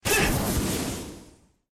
doublejump.ogg